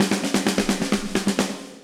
Index of /musicradar/80s-heat-samples/130bpm
AM_MiliSnareC_130-02.wav